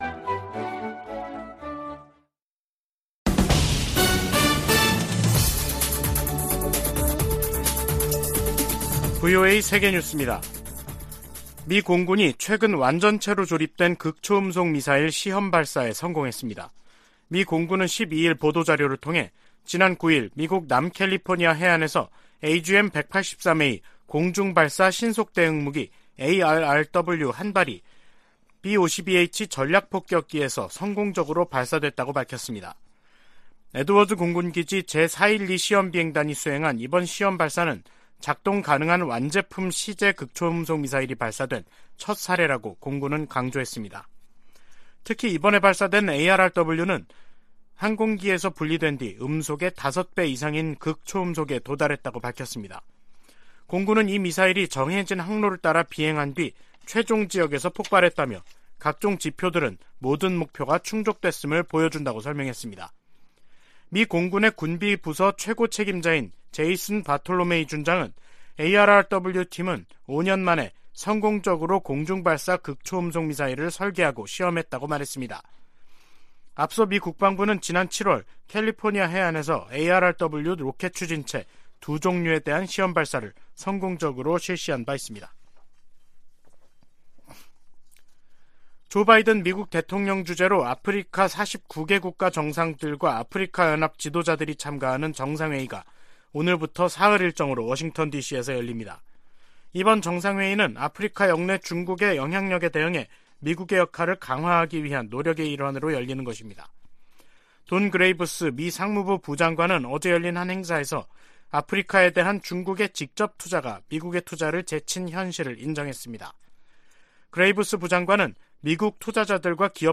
VOA 한국어 간판 뉴스 프로그램 '뉴스 투데이', 2022년 12월 13일 3부 방송입니다. 미국과 한국 외교당국 차관보들이 오늘 서울에서 만나 북한 비핵화를 위한 국제사회의 공동 대응을 거듭 강조했습니다. 유럽연합 EU가 북한 김정은 정권의 잇따른 탄도미사일 발사 등에 대응해 북한 국적자 8명과 기관 4곳을 독자 제재 명단에 추가했습니다.